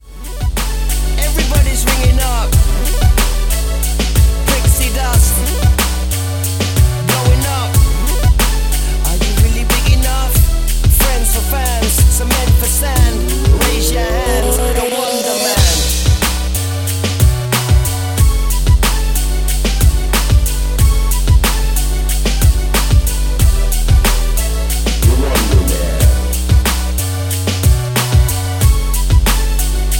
Bm
MPEG 1 Layer 3 (Stereo)
Backing track Karaoke
Pop, Duets, 2010s